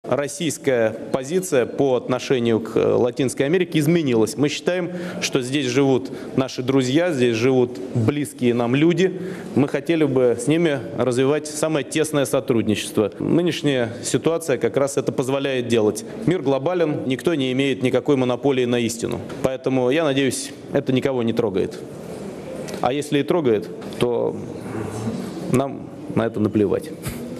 Фрагмент аудизаписи с выступлением президента РФ на пресс-конференции в Аргентине можно послушать здесь.